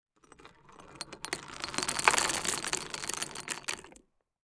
Sand_Pebbles_07.wav